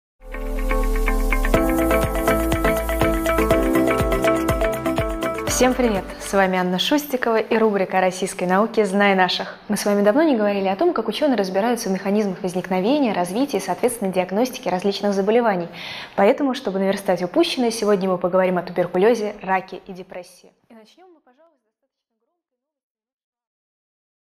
Аудиокнига О туберкулезе раке и депрессии | Библиотека аудиокниг